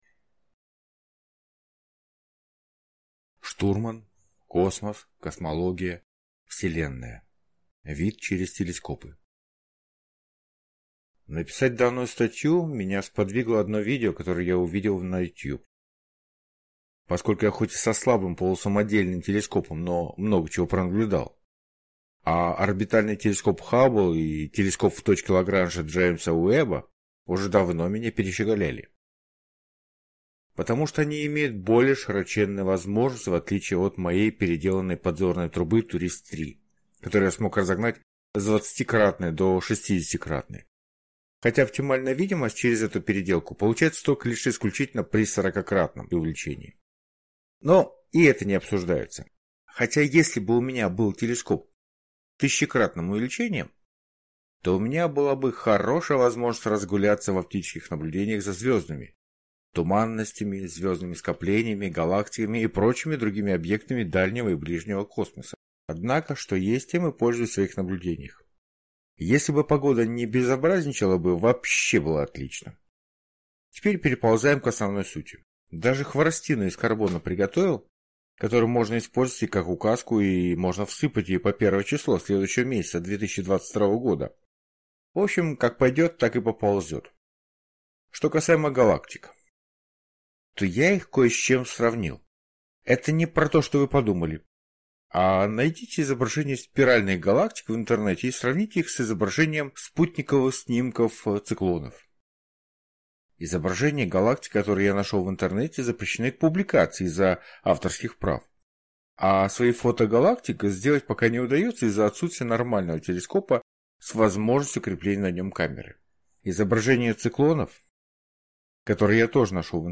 Аудиокнига Космос, космология, Вселенная. Вид через телескопы | Библиотека аудиокниг